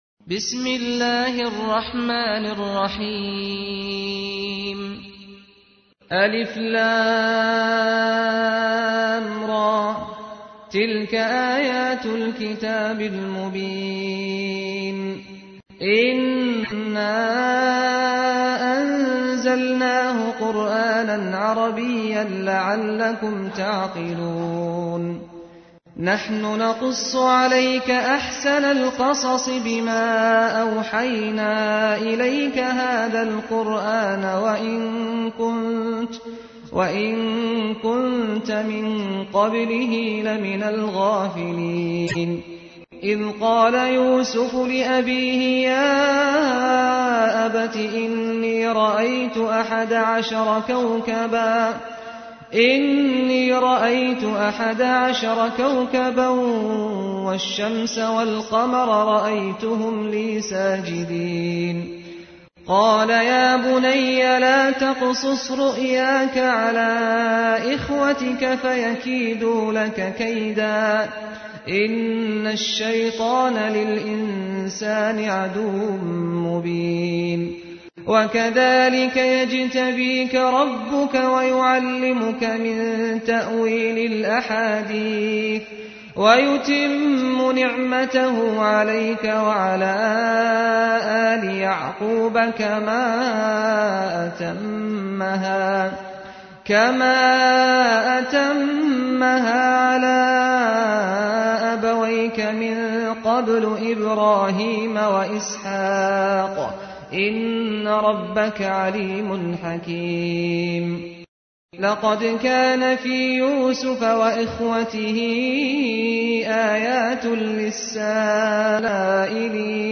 تحميل : 12. سورة يوسف / القارئ سعد الغامدي / القرآن الكريم / موقع يا حسين